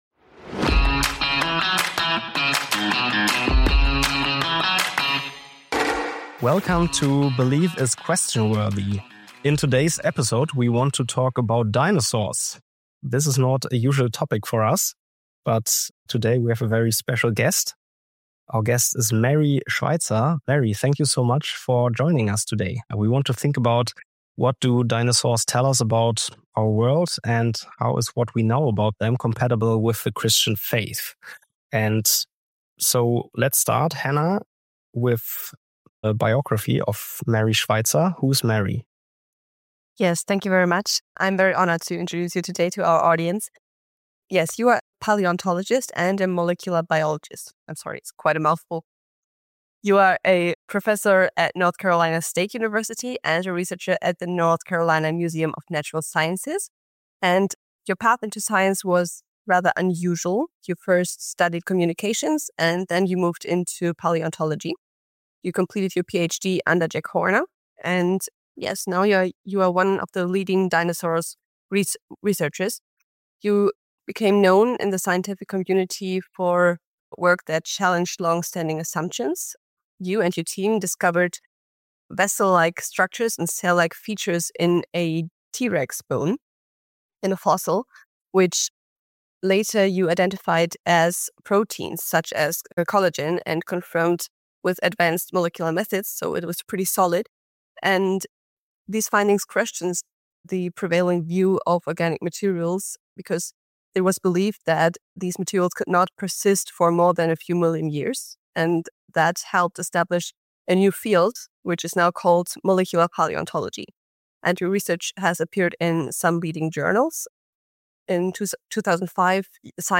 God’s undertaker: Did dinosaurs bury God? - A Conversation with Prof. Dr. Mary B. Schweitzer (Paleotomology, North Carolina State University)